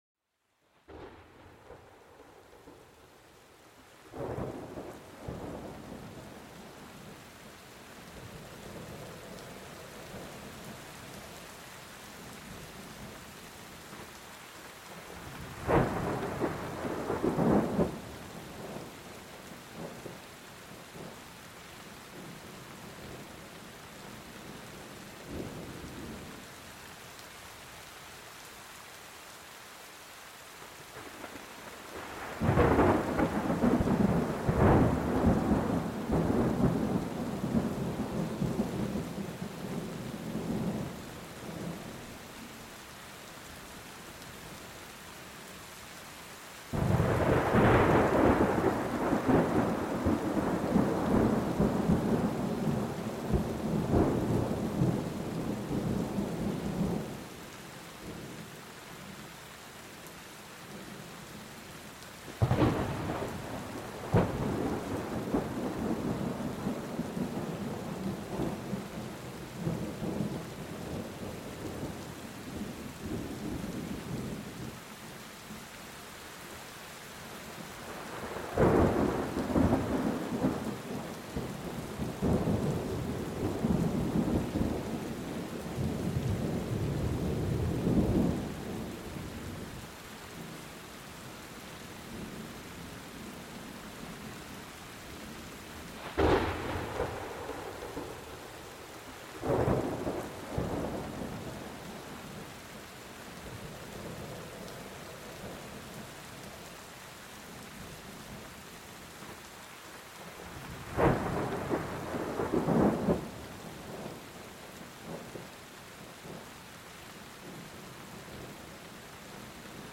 Vive la intensidad de una tormenta en formación, donde cada rayo anuncia el retumbar del trueno.